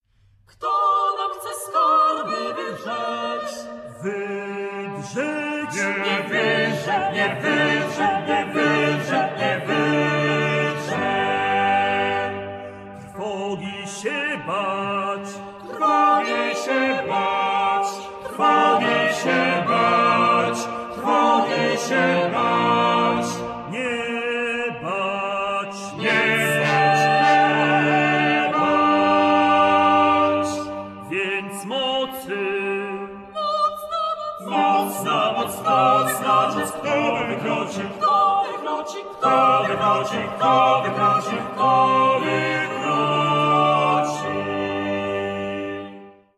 sopran
fidel
harfa, bęben, śpiew
puzon
tenor